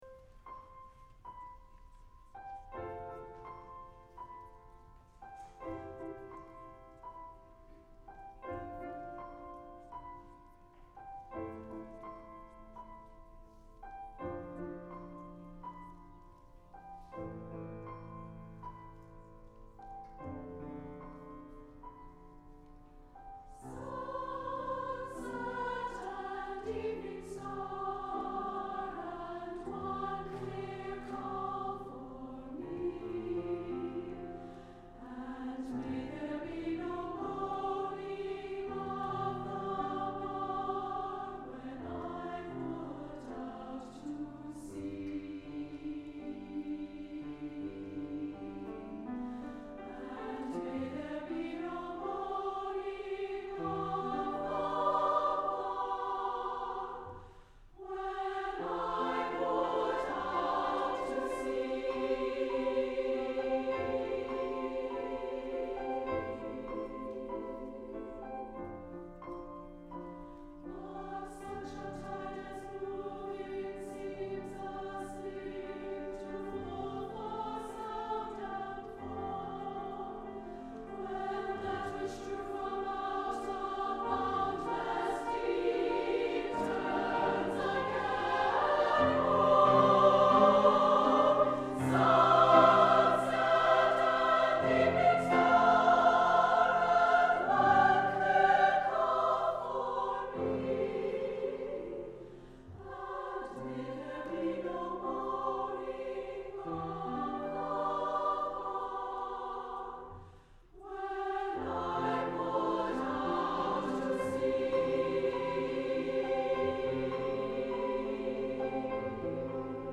SSAA and piano